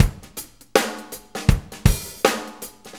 Shuffle Loop 23-07.wav